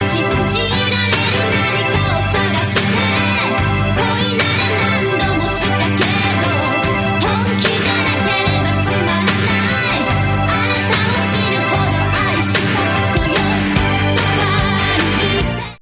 このテのグループでは今も昔も変わらないソロ＋ユニゾンという伝統のスタイル。